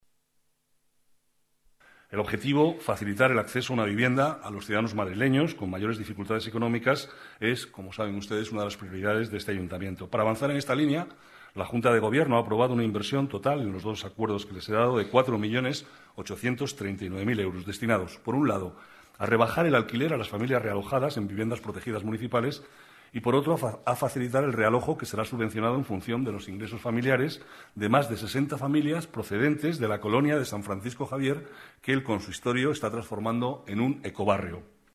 Nueva ventana:Declaraciones vicealcalde, Manuel Cobo: Junta Gobierno, subvenciones alquiler